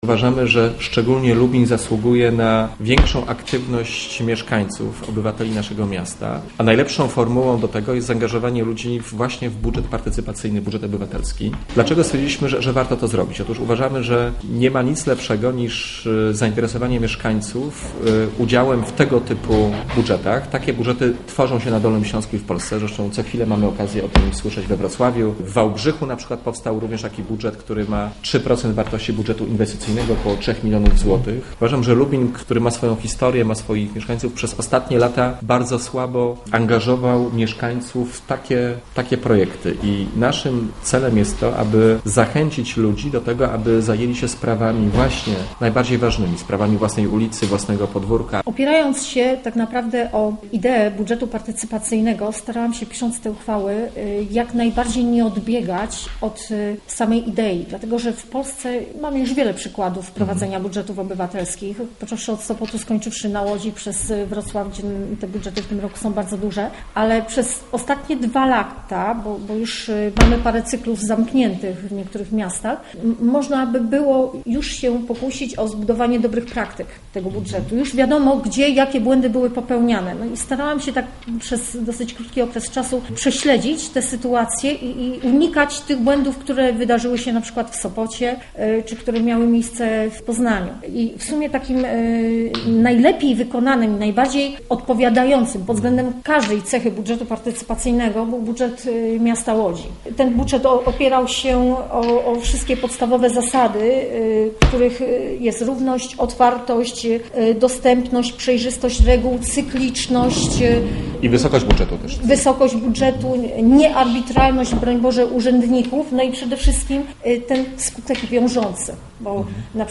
Europoseł Piotr Borys; radni Porozumienia Samorządowego: Ksenia Dowhań - Domańska i Krzysztof Olszowiak: